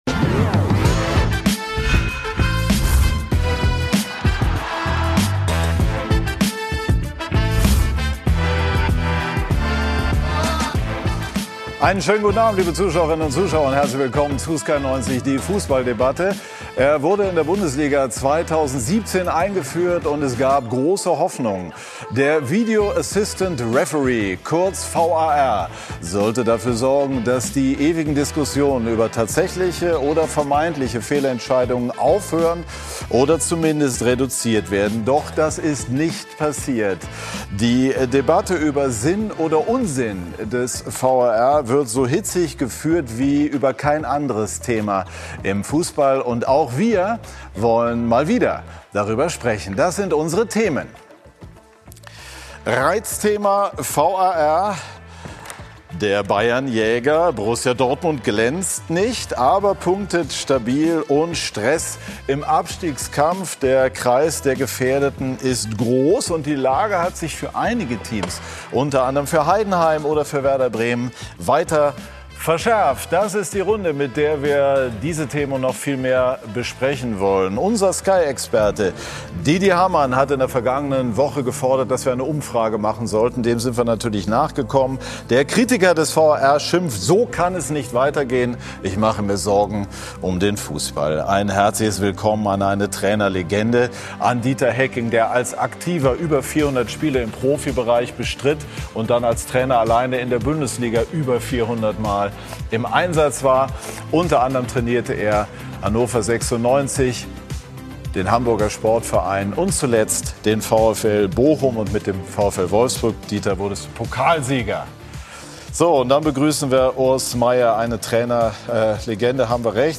Beschreibung vor 2 Monaten Kontrovers, unterhaltsam, meinungsbildend – mit Sky90 präsentiert Sky den umfassendsten Fußball-Live-Talk Deutschlands. Immer sonntags ab 18:00 Uhr begrüßt Moderator Patrick Wasserziehr kompetente Gäste im Sky Studio.